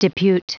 Prononciation du mot depute en anglais (fichier audio)
Prononciation du mot : depute